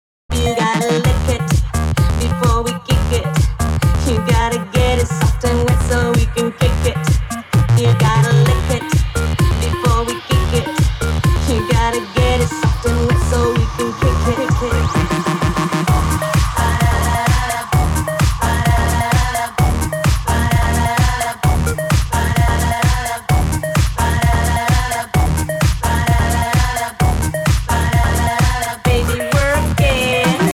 • Качество: 320, Stereo
поп
dance
Старенькая клубная нарезка